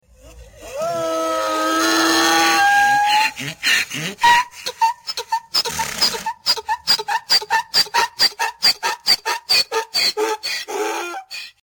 دانلود صدای چند خر در طویله از ساعد نیوز با لینک مستقیم و کیفیت بالا
جلوه های صوتی